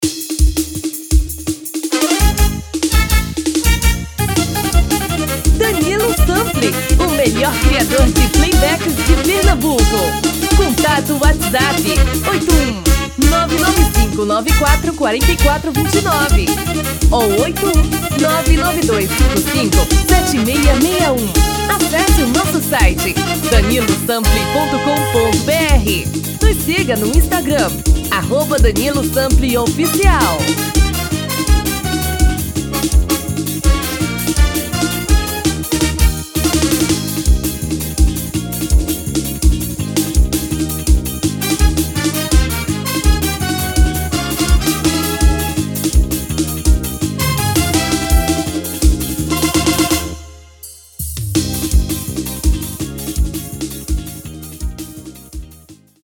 Obs: Produzido no tom original e temos com tom Feminina.
TOM FEMININO